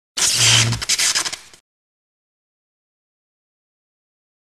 Electric Shock
Category: Sound FX   Right: Personal